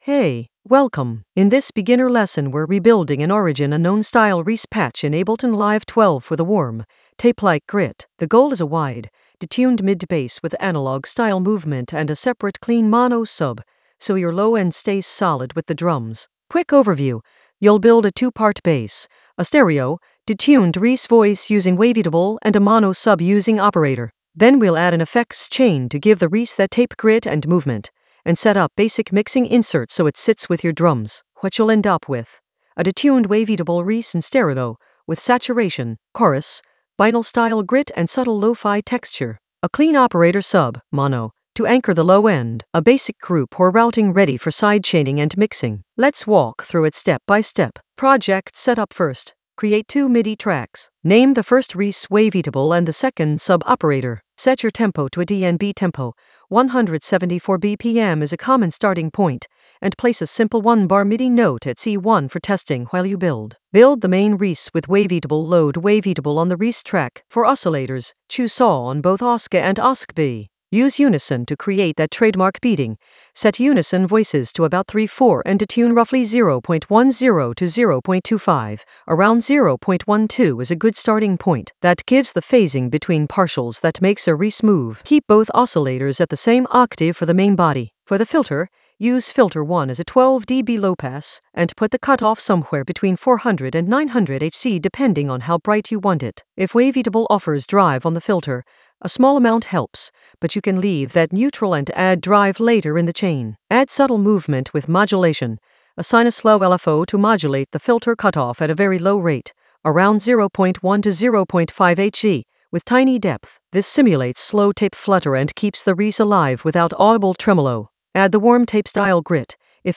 An AI-generated beginner Ableton lesson focused on Origin Unknown approach: rebuild a reese patch in Ableton Live 12 for warm tape-style grit in the Drums area of drum and bass production.
Narrated lesson audio
The voice track includes the tutorial plus extra teacher commentary.